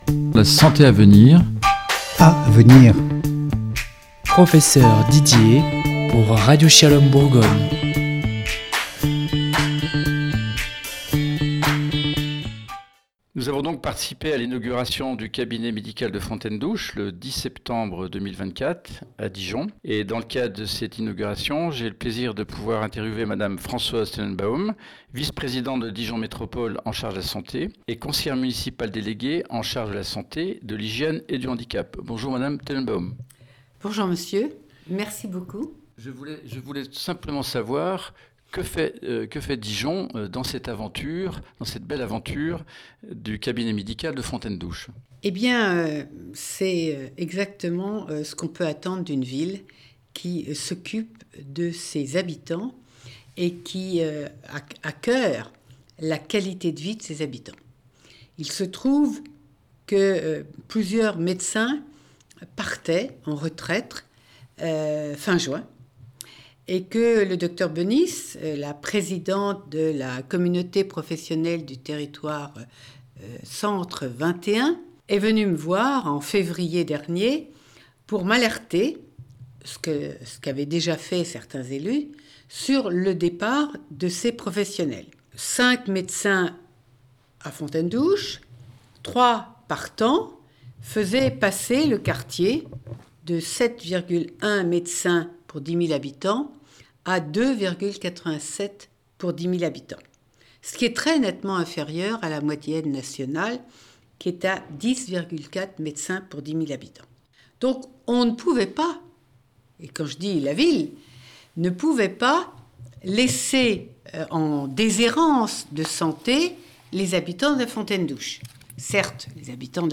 Un nouveau cabinet médical pour Fontaine-d'Ouche-Entrevue avec Françoise Tenenbaum
Nous avons rencontré Madame Françoise Tenenbaum, conseillère municipale déléguée en charge de la santé, de l'hygièneet du handicap et vice-présidente de Dijon Métropole en charge de la santé. Elle nous explique l'importance de la réalisation d'un tel projet en le mettant en perspective avec les enjeux relatifsà la prévention et au développement d'une offre de soins adaptée sur tous les territoires et pour toutes les populations.